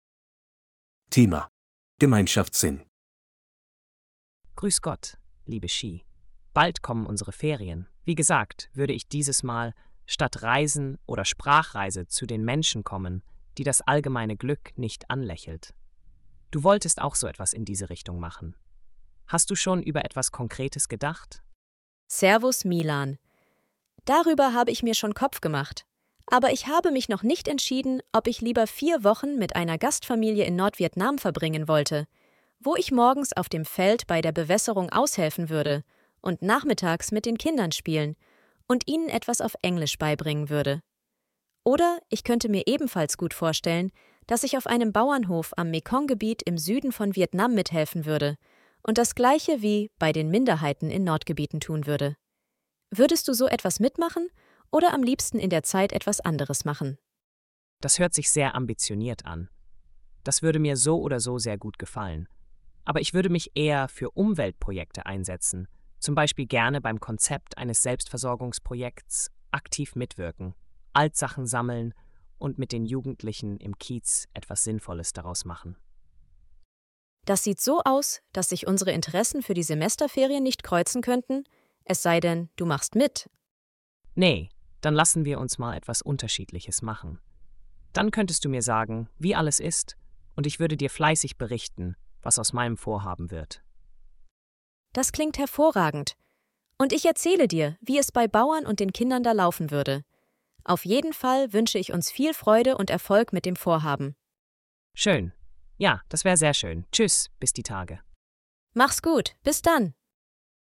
Hörtext für die Dialoge bei Aufgabe 4: